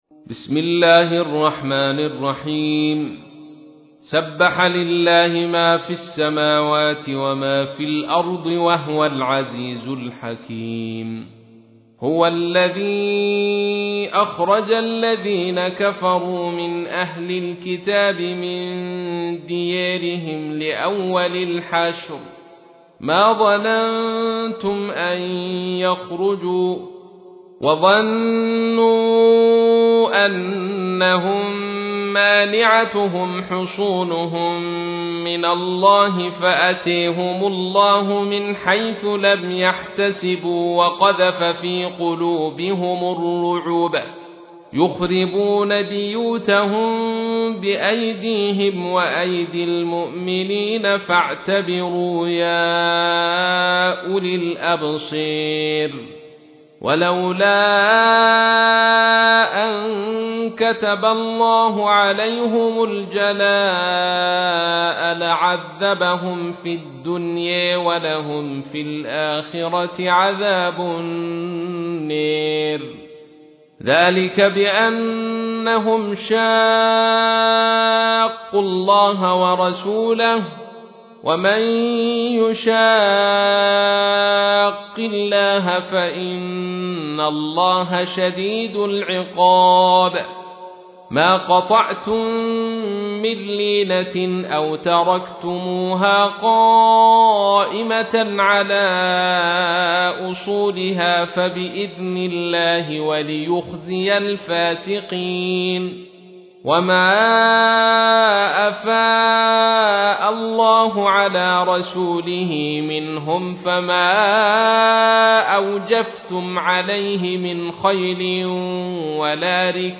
تحميل : 59. سورة الحشر / القارئ عبد الرشيد صوفي / القرآن الكريم / موقع يا حسين